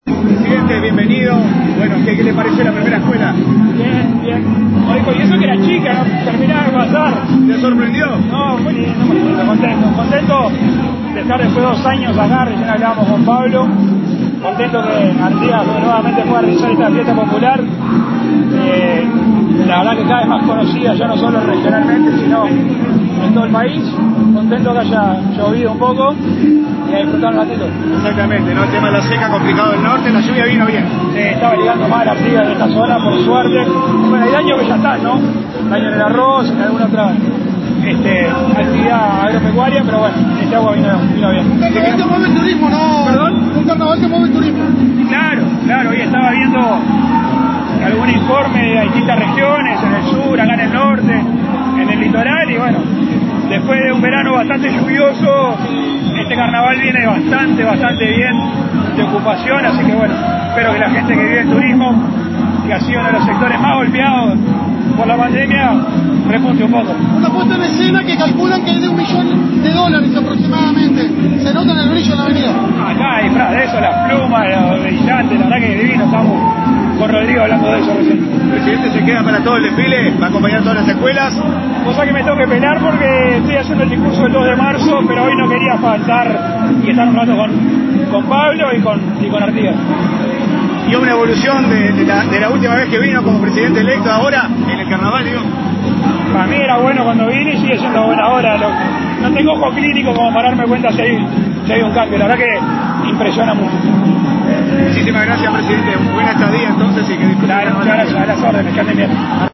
Declaraciones a la prensa del presidente de la República, Luis Lacalle Pou, en el Carnaval de Artigas 2022